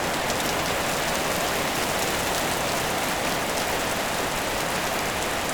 rain_heavy_loop_01.wav